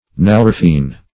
nalorphine - definition of nalorphine - synonyms, pronunciation, spelling from Free Dictionary
nalorphine.mp3